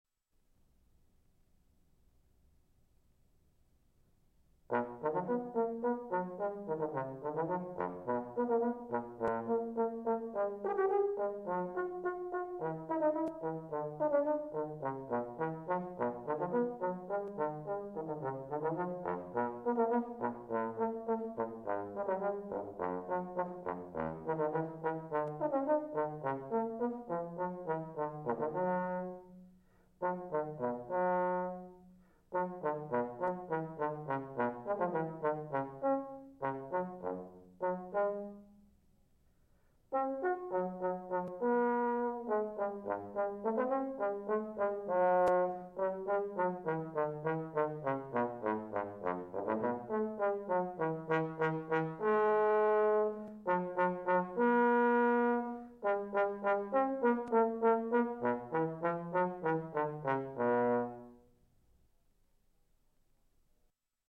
Audition Tape (April 1984)
Douglas Yeo, bass trombone.